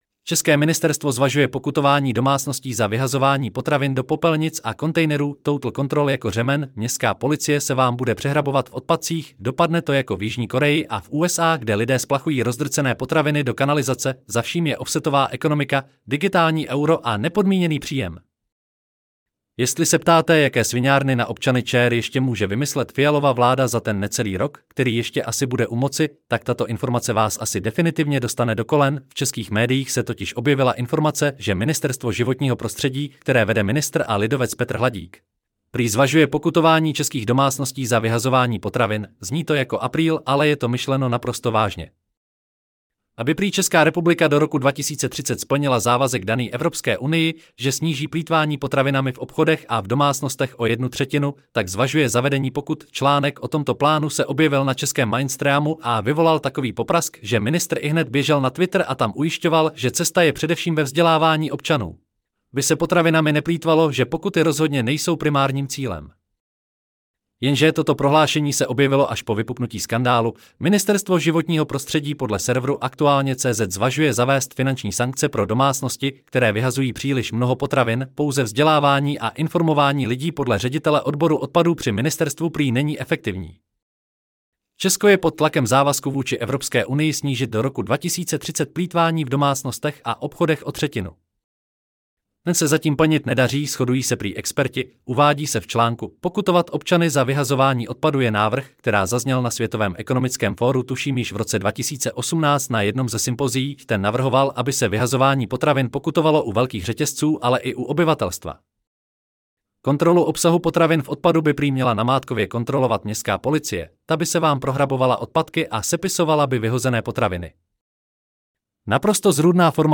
Celý článek si můžete poslechnout v audioverzi zde: Ceske-ministerstvo-zvazuje-pokutovani-domacnosti-za-vyhazovani-potravin-do-popelnic-a 12.11.2024 České ministerstvo zvažuje pokutování domácností za vyhazování potravin do popelnic a kontejnerů!